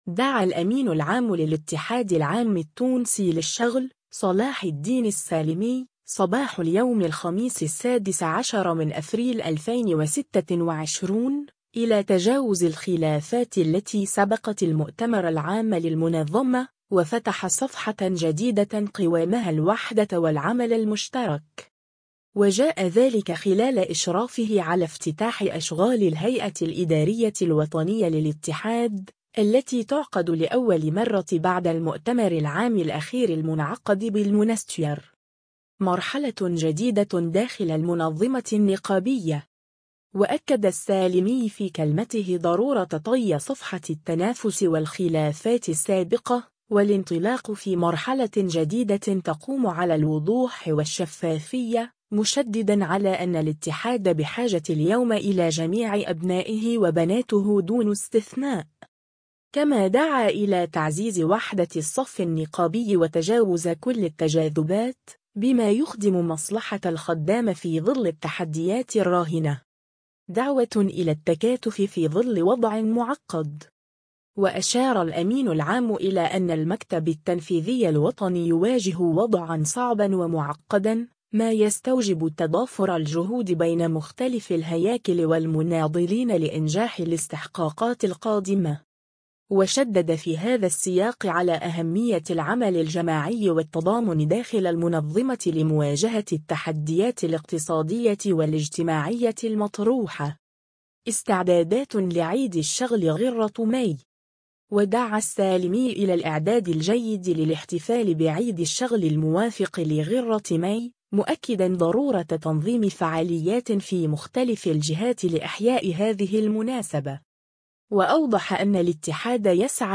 وجاء ذلك خلال إشرافه على افتتاح أشغال الهيئة الإدارية الوطنية للاتحاد، التي تُعقد لأول مرة بعد المؤتمر العام الأخير المنعقد بالمنستير.